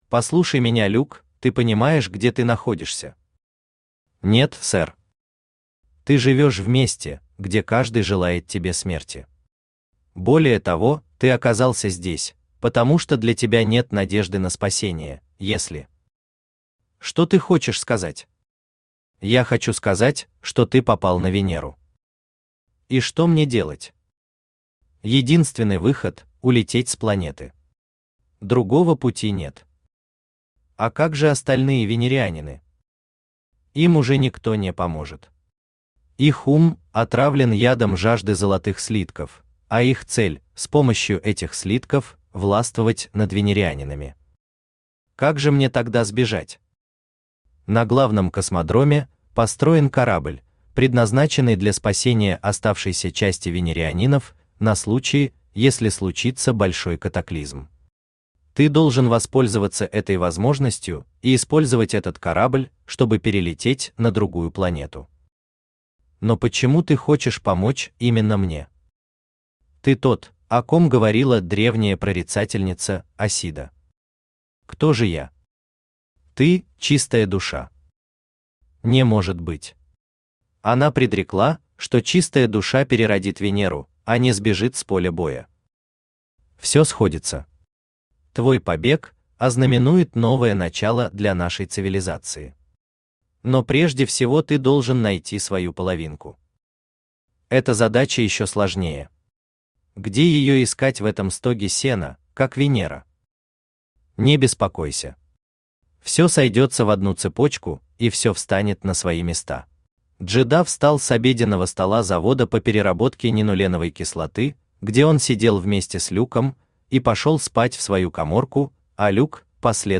Аудиокнига Перерождение Венеры | Библиотека аудиокниг
Aудиокнига Перерождение Венеры Автор Виталий Александрович Кириллов Читает аудиокнигу Авточтец ЛитРес.